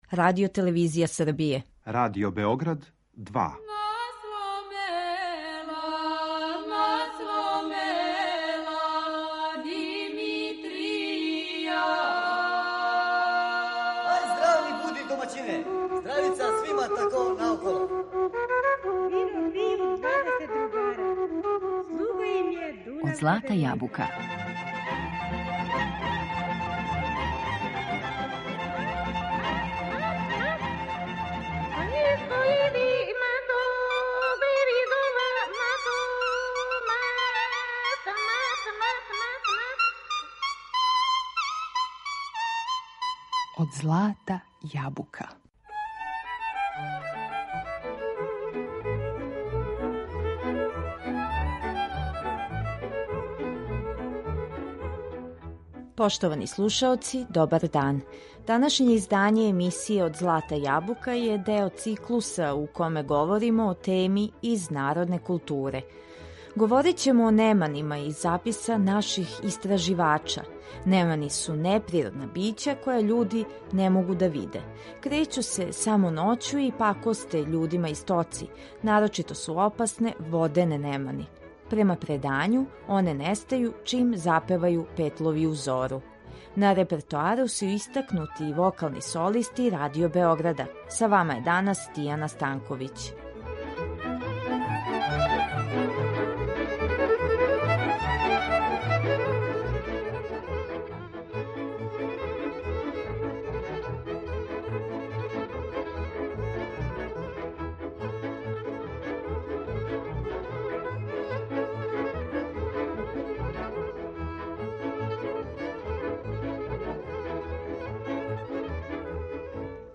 Овога пута ћемо говорити о неманима кроз записе из стручне литературе. На репертоару су истакнути вокални солисти Радио Београда.